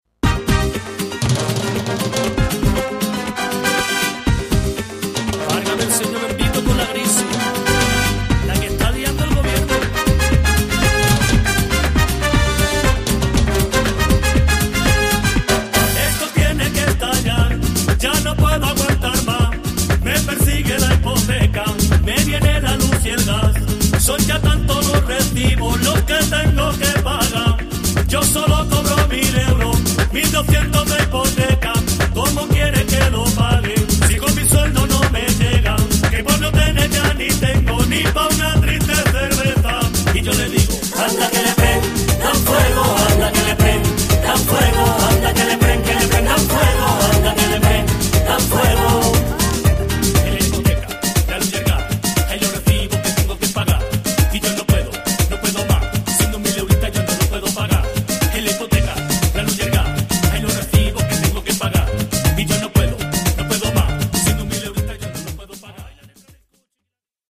最初から最後まで、彼らのルーツ音楽である『ルンバ・フラメンコ』に対する敬意・愛が溢れている！